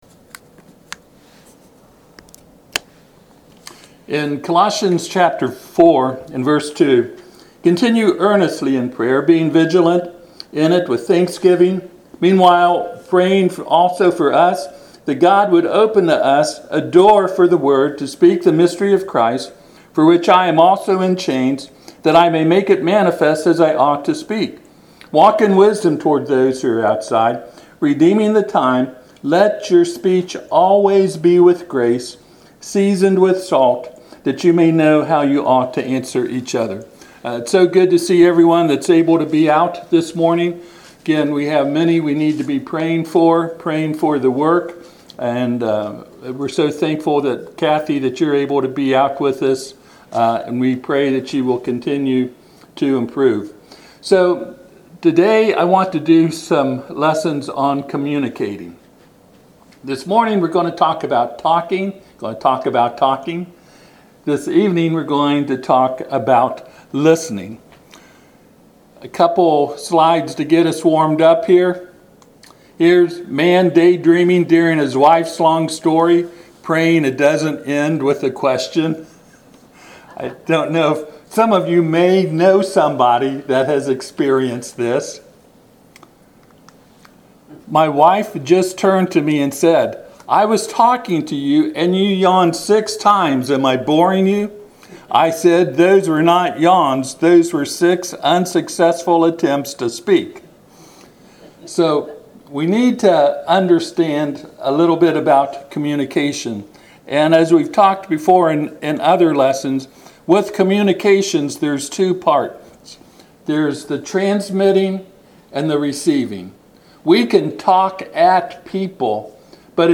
Passage: Colossians 4:2-6 Service Type: Sunday AM